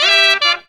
HARM RIFF 4.wav